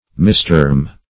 misterm - definition of misterm - synonyms, pronunciation, spelling from Free Dictionary
Misterm \Mis*term"\, v. t. To call by a wrong name; to miscall.